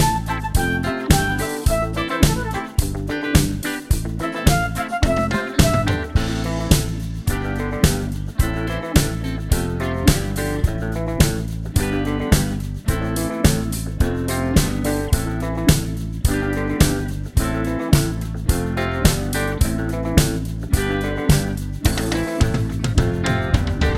Two Semitones Down Pop (1980s) 3:15 Buy £1.50